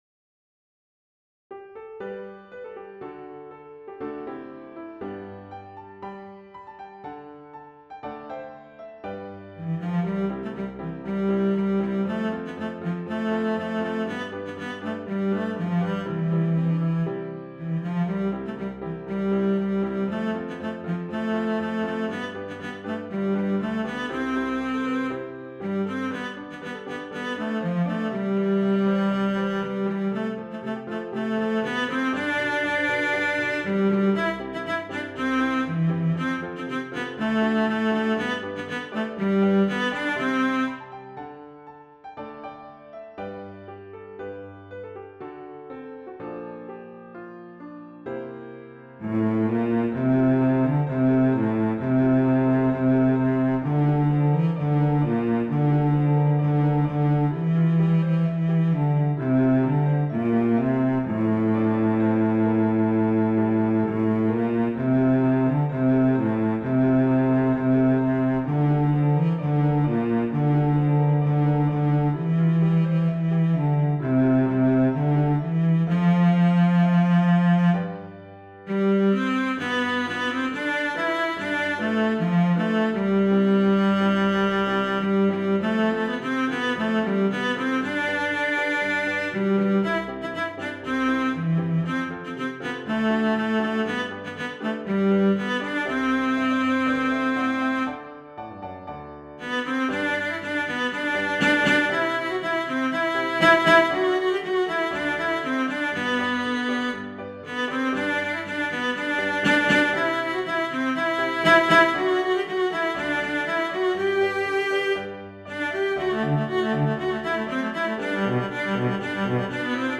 Christian, Gospel, Sacred.
set to a fast past, energetic jig